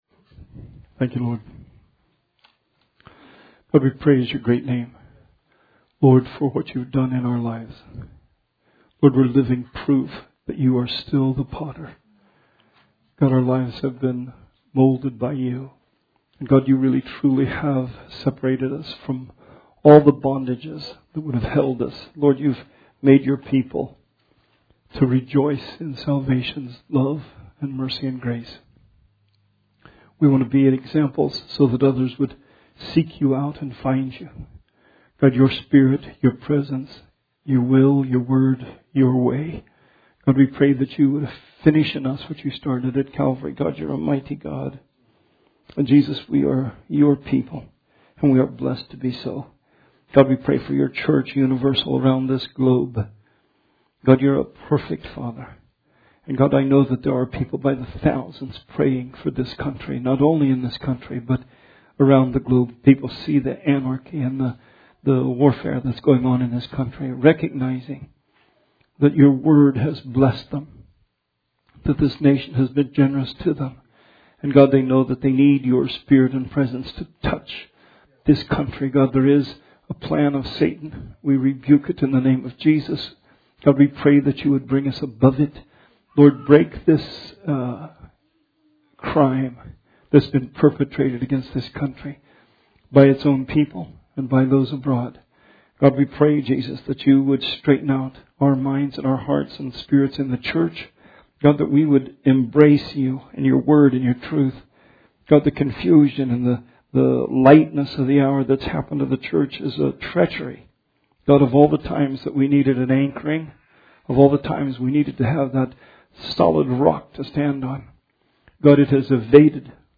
Series Bible Study